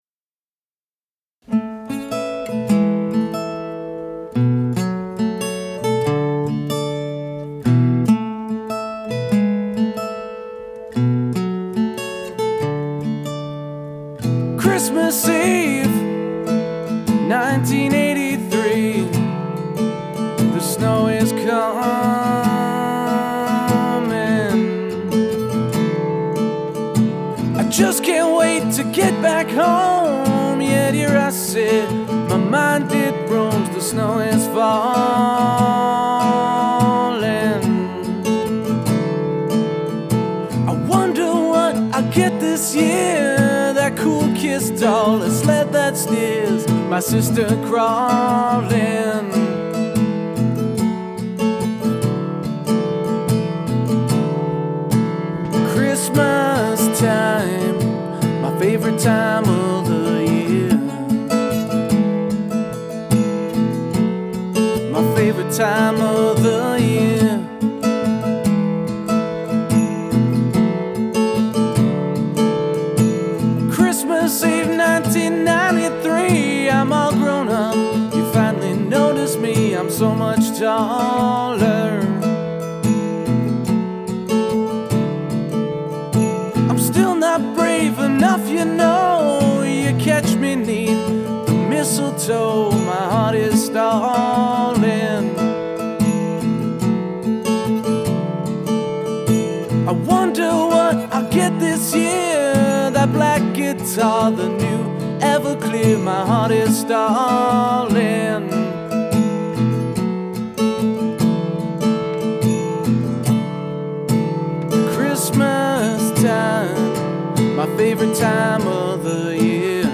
Acoustic-Folk-Gruppe